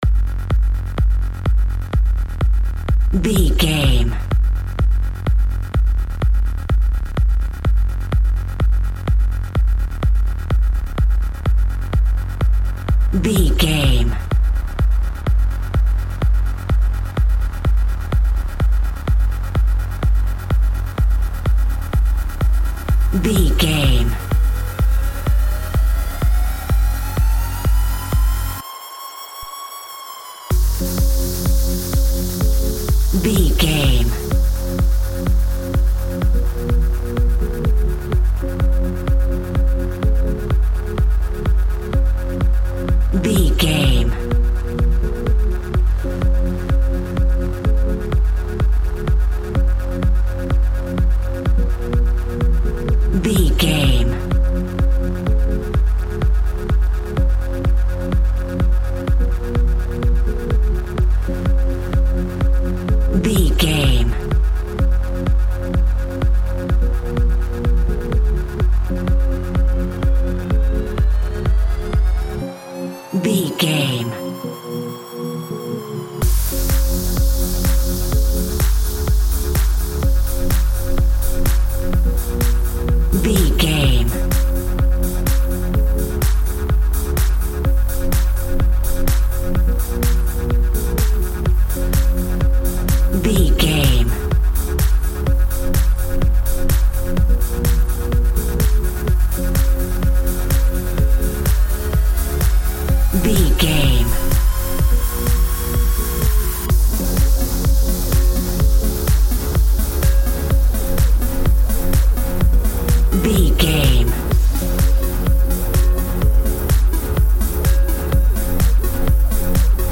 In-crescendo
Aeolian/Minor
F#
groovy
dreamy
smooth
futuristic
drum machine
synthesiser
house
electro dance
synth leads
synth bass
upbeat